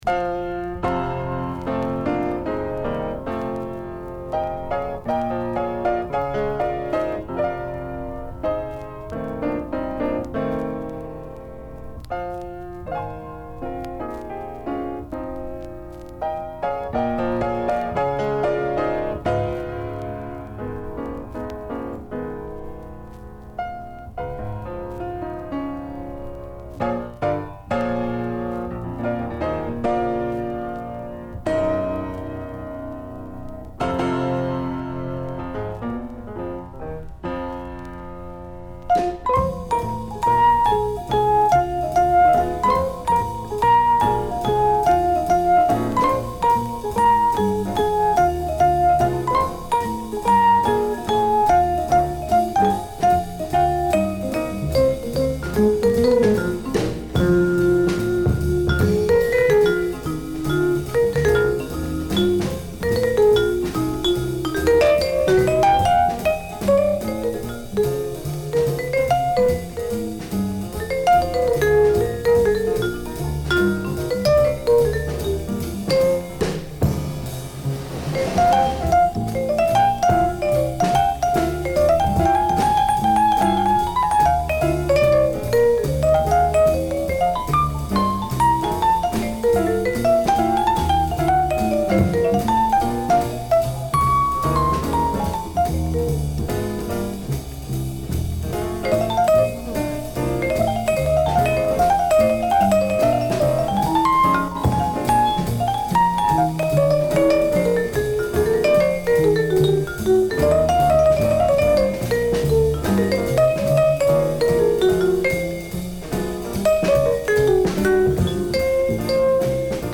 シカゴ出身のソウルフルなサックス奏者、